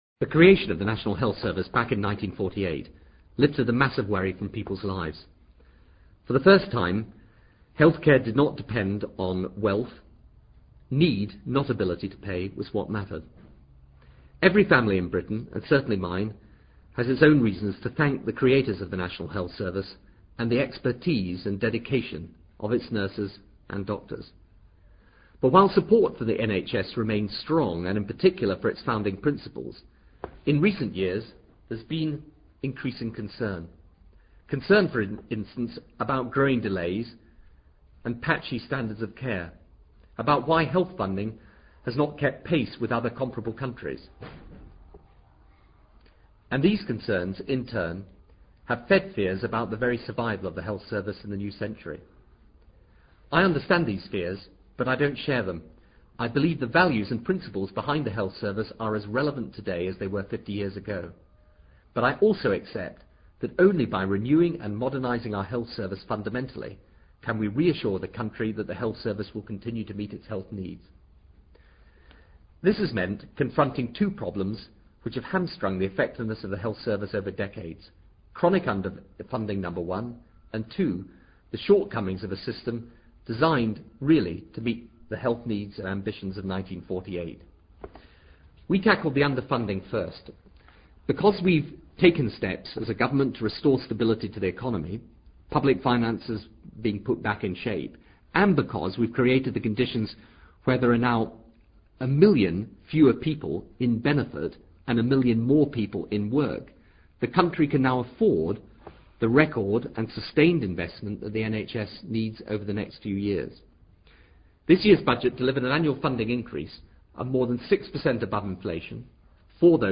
Transcript of the Prime Minister's Broadcast on the NHS Plan